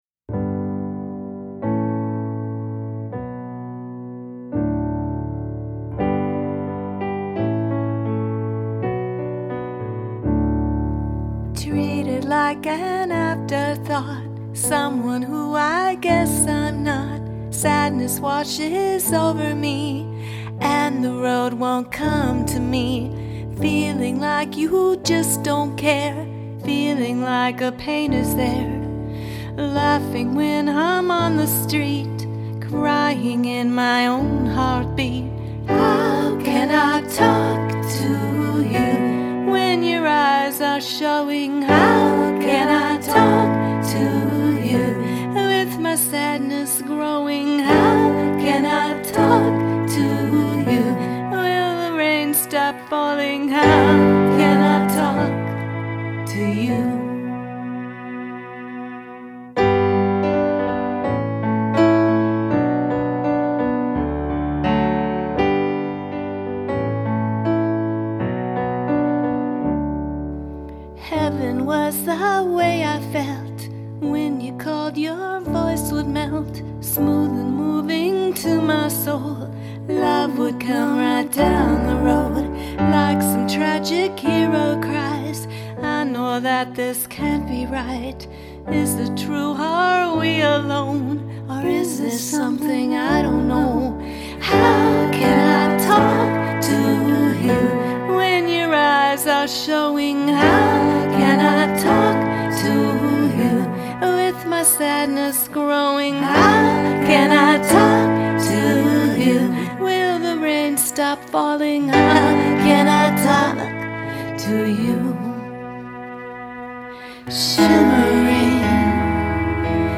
singer/songwriters